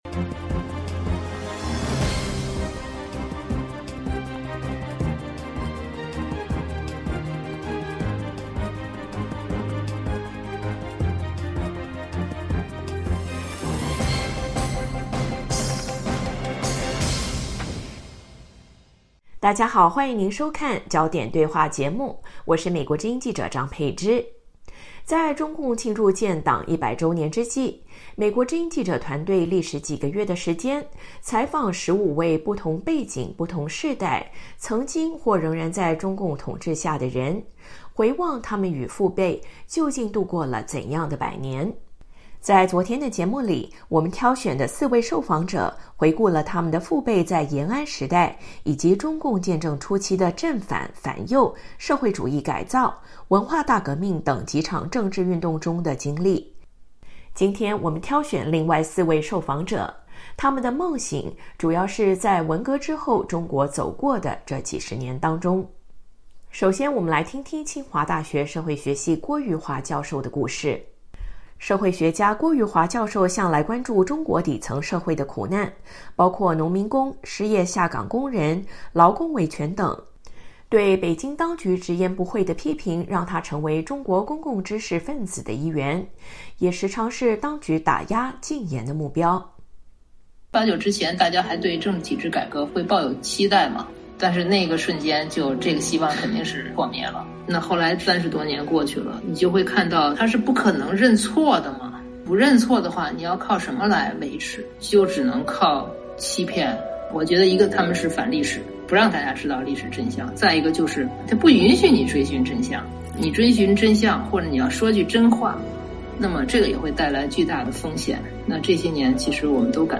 美国之音记者团队历时几个月的时间采访了15位不同世代、不同背景，曾经或仍在中共统治下生活的人，回望他们与父辈究竟走过了怎样的百年。